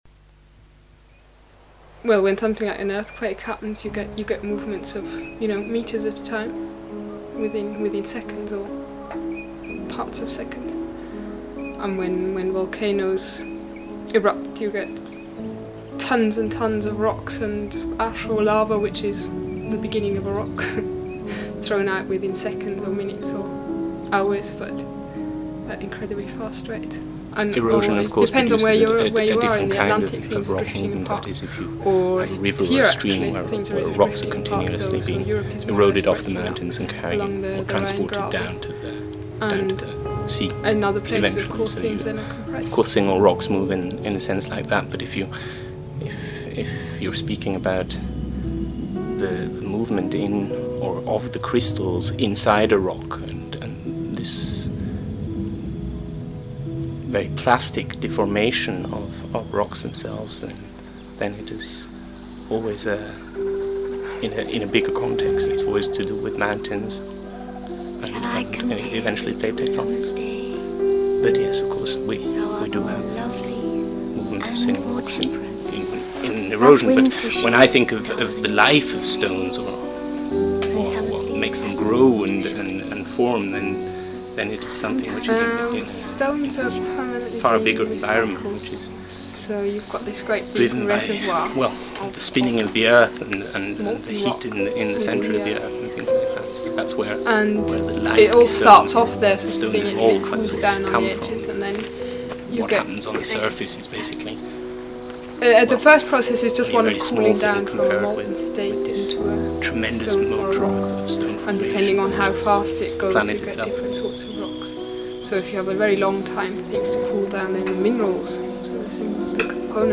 Moving Stones produces the movement of rocks through the narrations of two geologists and the memory of its experience. It unfolds and creates the motion, and produces, rather than presents, the context of the action.